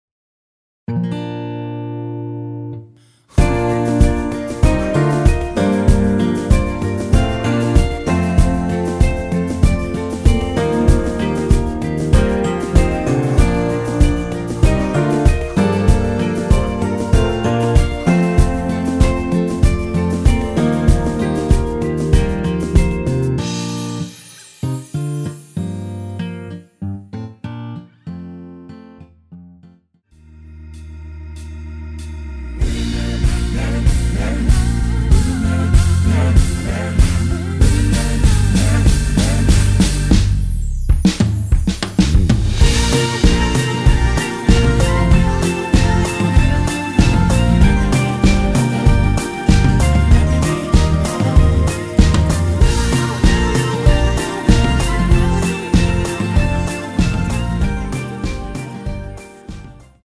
원키 코러스 포함된 MR 입니다. 전주 없이 시작 하는 곡이라 1마디 전주 만들어 놓았습니다.
원곡의 보컬 목소리를 MR에 약하게 넣어서 제작한 MR이며